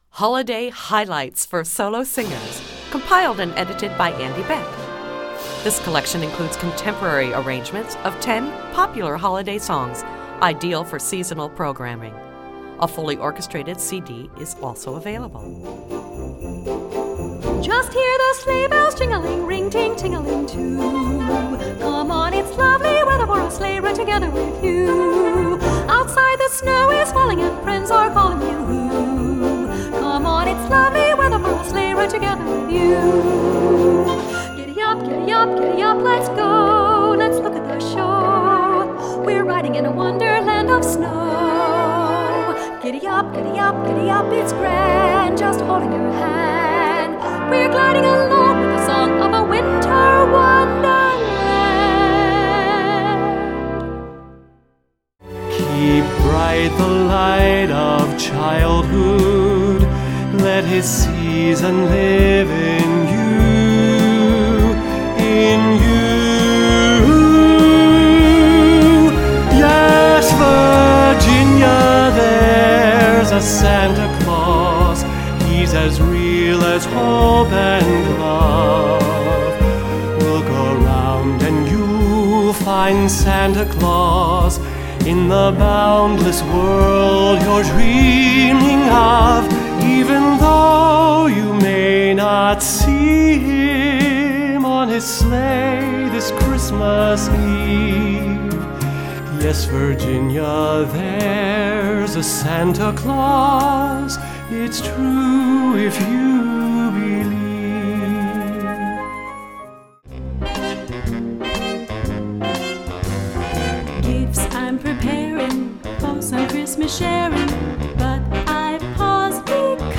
Voicing: Vocal Collection